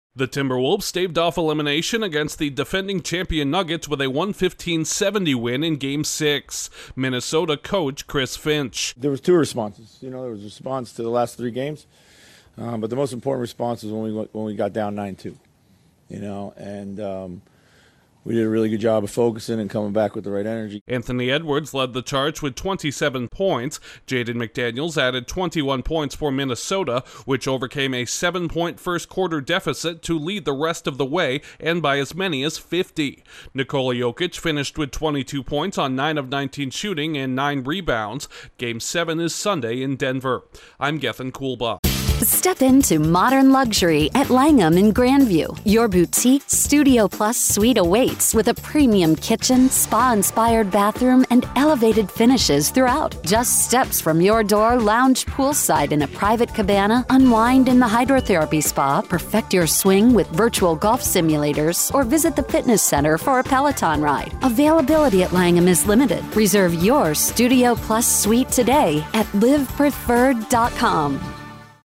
The Timberwolves post a lopsided victory that creates a Game 7 in Denver. Correspondent